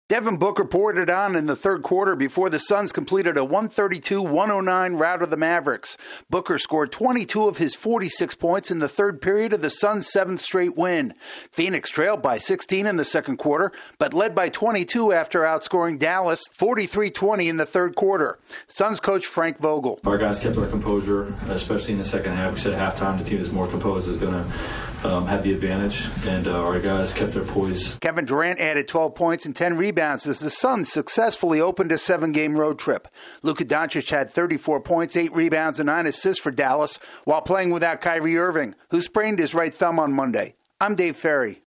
The Suns win behind their sharp-shooting guard. AP correspondent